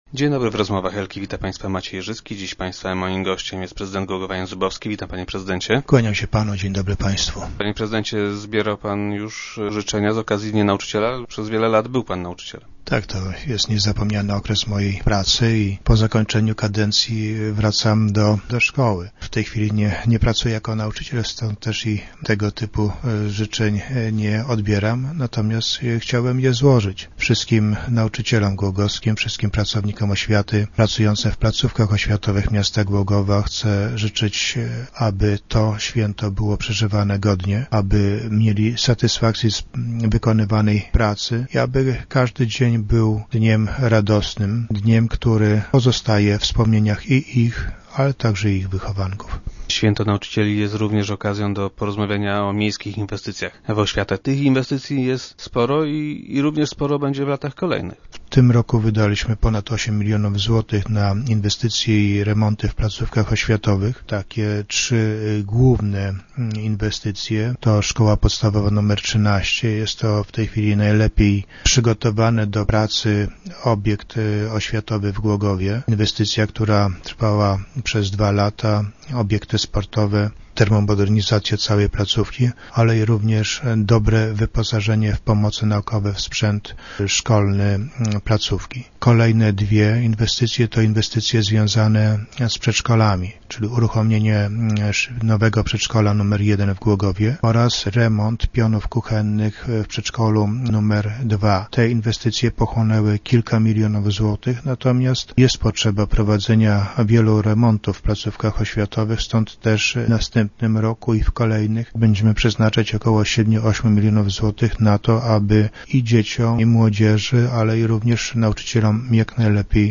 Powtórzył je w dzisiejszych Rozmowach Elki.